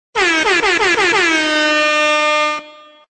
MLG Horns Sound Effect